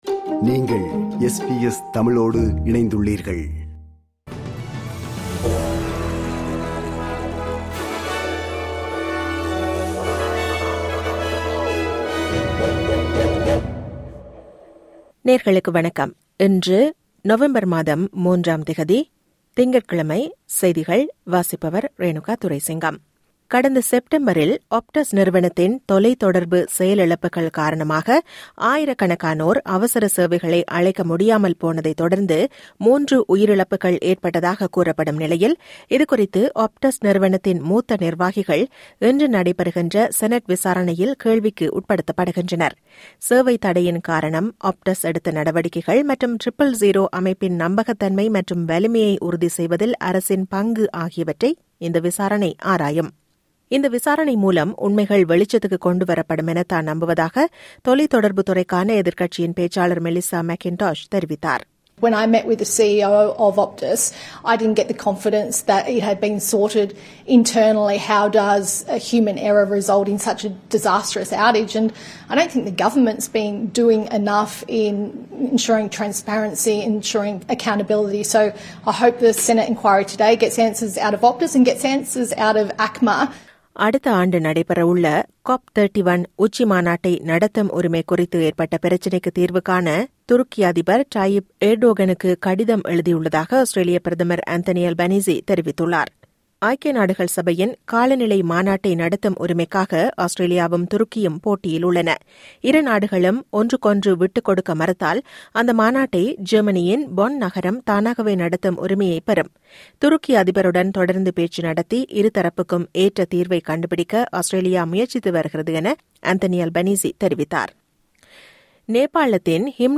இன்றைய செய்திகள்: 03 நவம்பர் 2025 - திங்கட்கிழமை
SBS தமிழ் ஒலிபரப்பின் இன்றைய (திங்கட்கிழமை 03/11/2025) செய்திகள்.